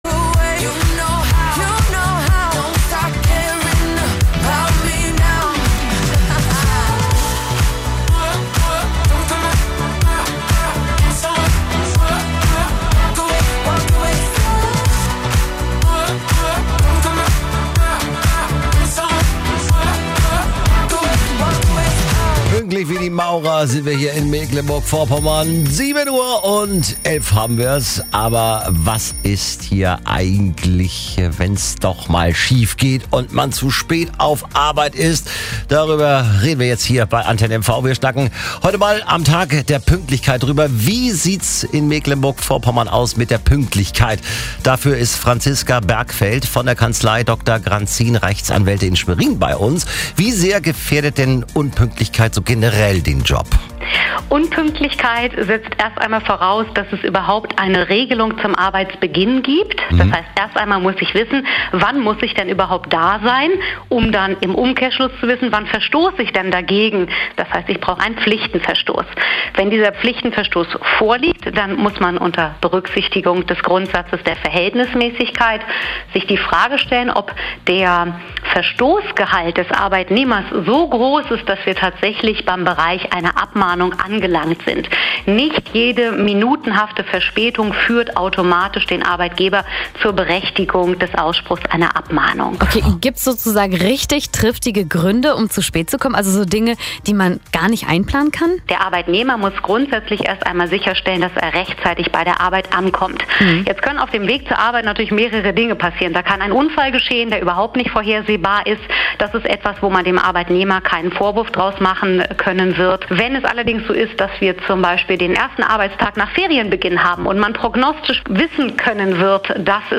Pünktlichkeit am Arbeitsplatz - Radiointerview